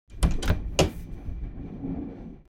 جلوه های صوتی
دانلود صدای قطار 10 از ساعد نیوز با لینک مستقیم و کیفیت بالا